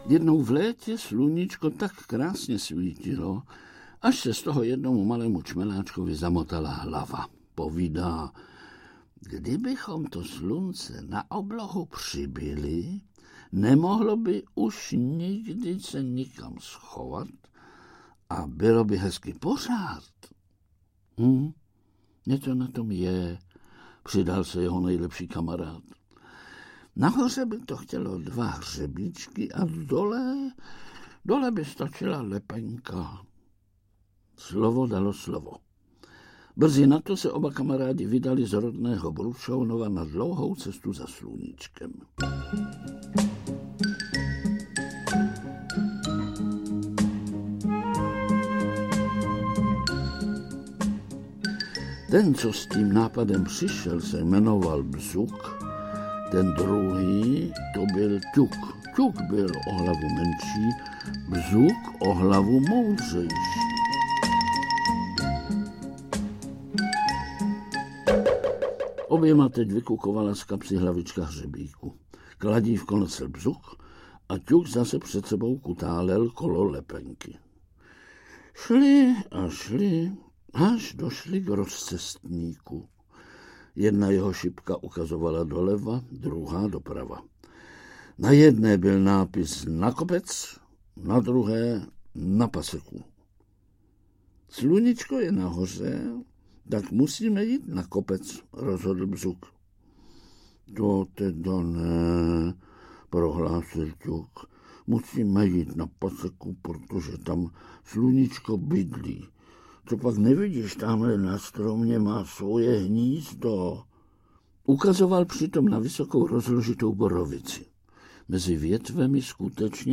Ukázka z knihy
Půvabné vyprávění je okořeněno interpretačním mistrovstvím,humorem a vlídností Vlastimila Brodského, se skvělým, charakterizujícím hudebním doprovodem Jaroslava Celby.
• InterpretVlastimil Brodský